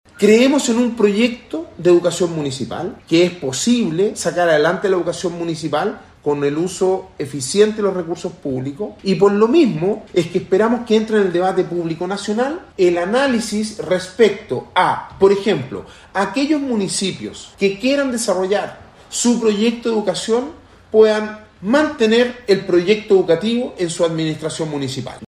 Por su parte, el alcalde de Pucón, Sebastián Álvarez, señaló que dicha propuesta se sustenta en el uso eficiente de los recursos públicos.